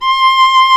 Index of /90_sSampleCDs/Roland - String Master Series/STR_Viola Solo/STR_Vla1 % marc